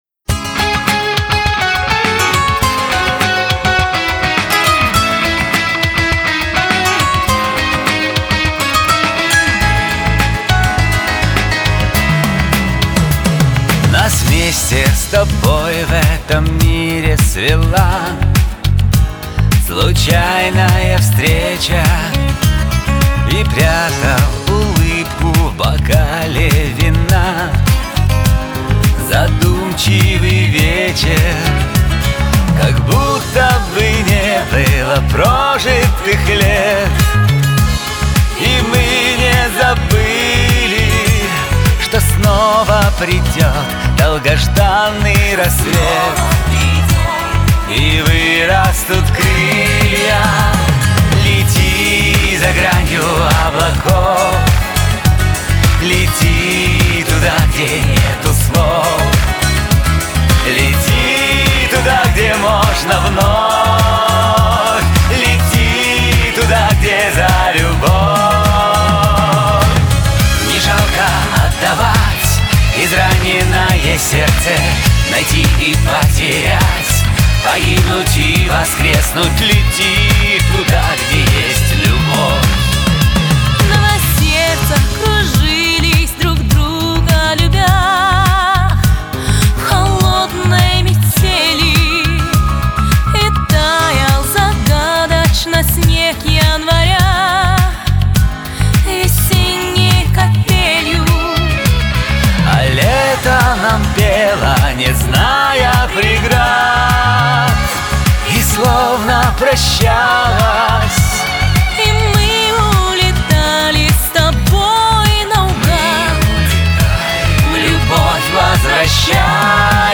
Вот еще попса:)))